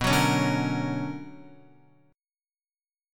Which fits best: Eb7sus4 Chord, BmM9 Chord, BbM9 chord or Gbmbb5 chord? BmM9 Chord